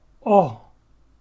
ɒ-individual.mp3